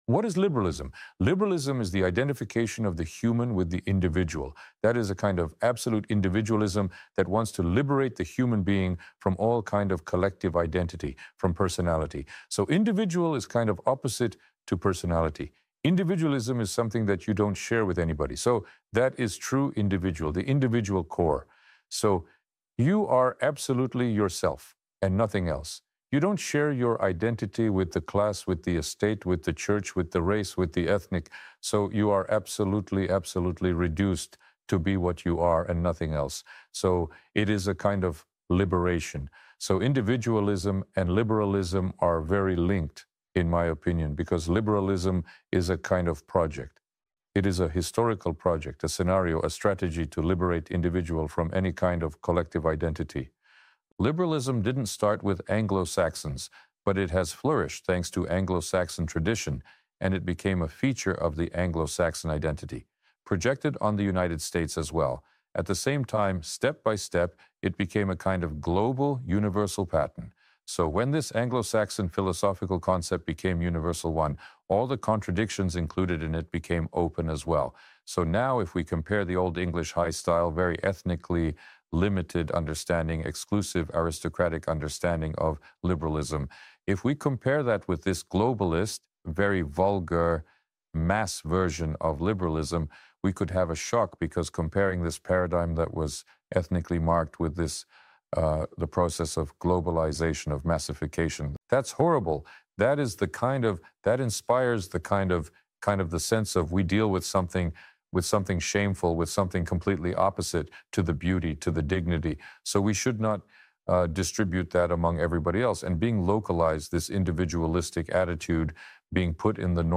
AI Dugin explains how this Anglo-Saxon idea became a vulgar globalized monster